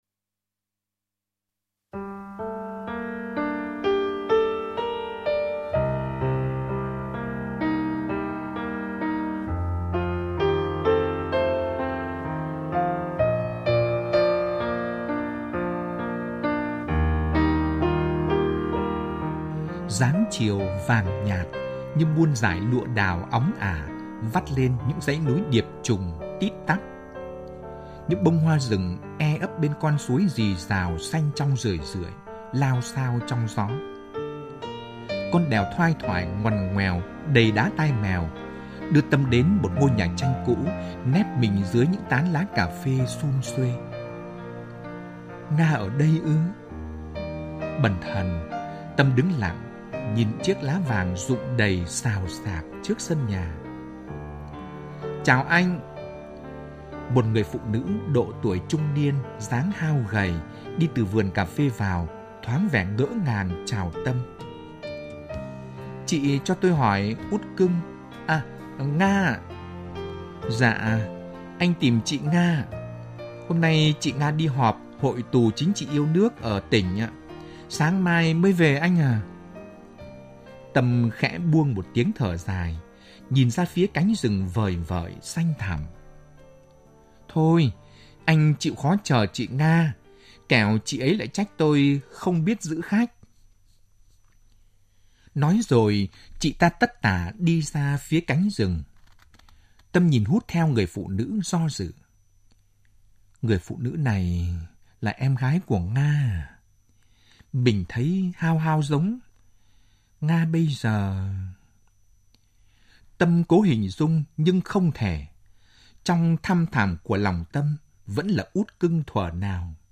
Nghe truyện tại đây